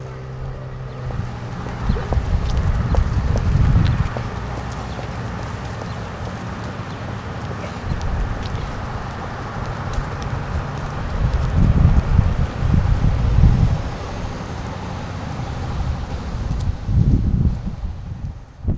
Traffic and pedestrians.wav